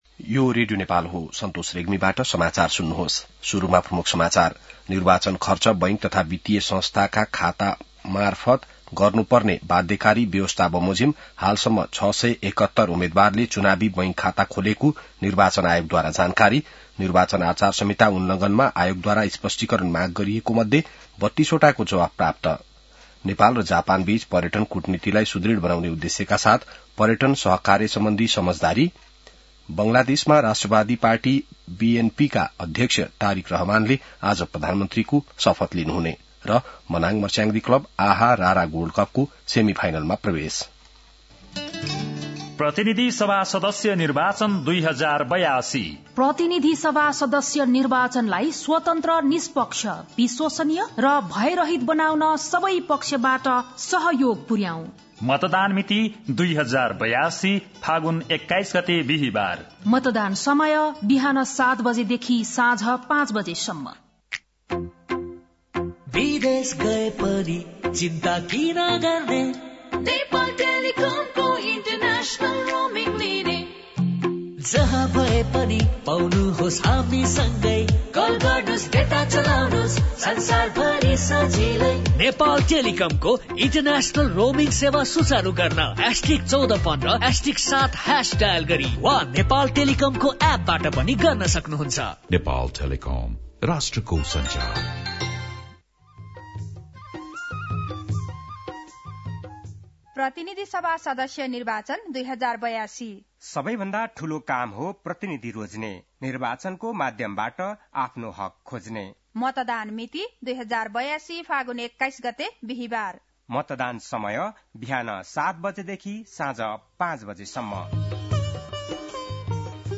बिहान ७ बजेको नेपाली समाचार : ५ फागुन , २०८२